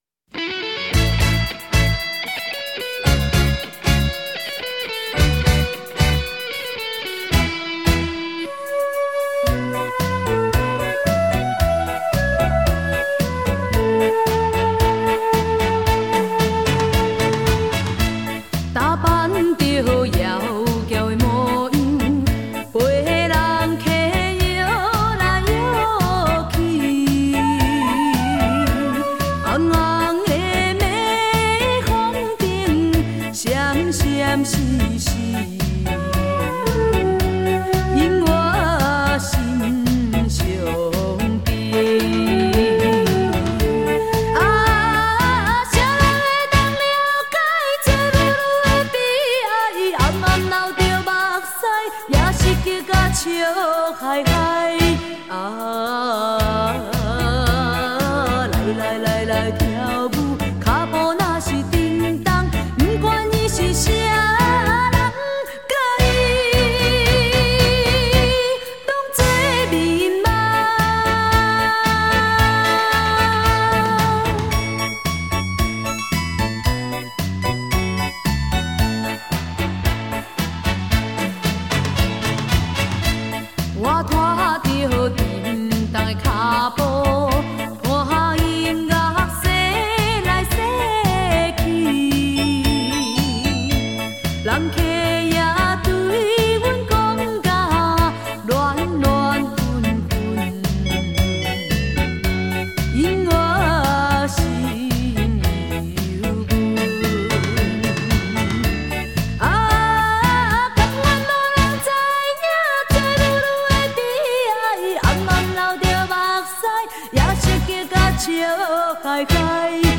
舞厅规格
恰恰歌唱版